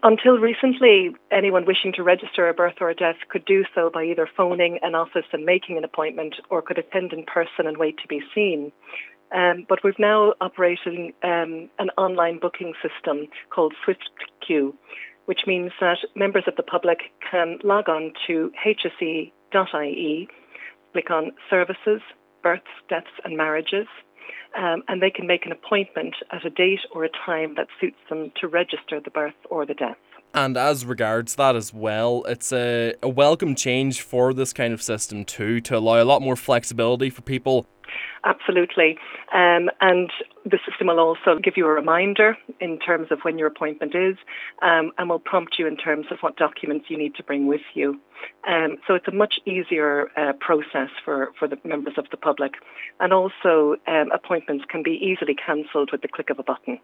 She explains how the new system works: